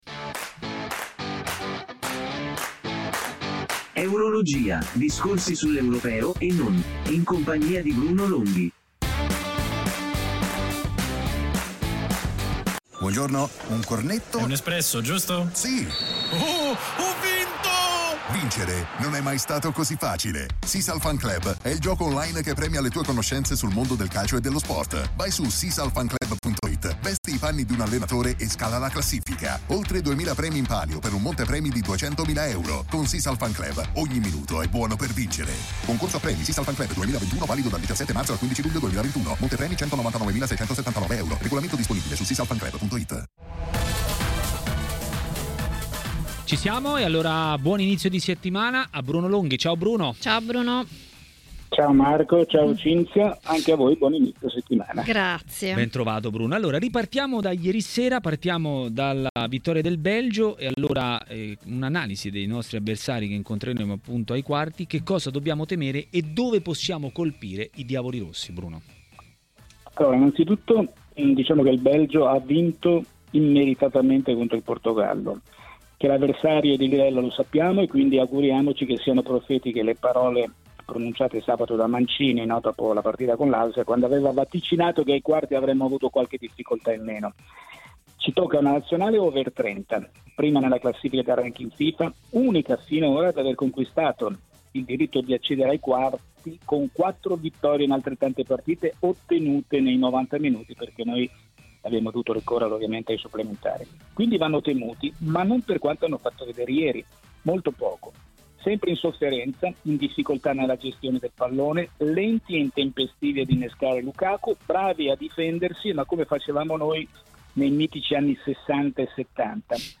A TMW Radio, per "Eurologia", il giornalista Bruno Longhi ha detto la sua sugli Europei 2020.